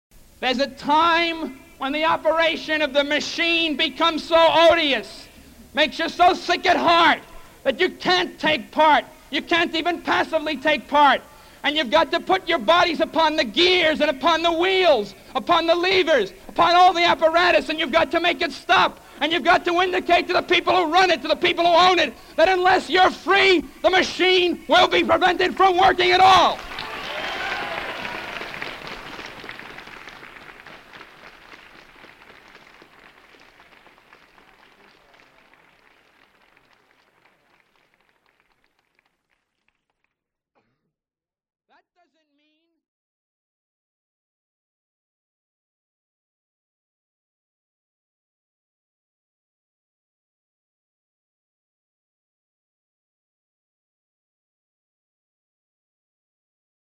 Savio is remembered for the words he spoke on Dec. 2, 1964, from Sproul Plaza in front of Berkeley's main administration building, to a large crowd of protesters, many of whom took part in a sit-in inside the building and a campus strike.
Mario Savio at FSM rally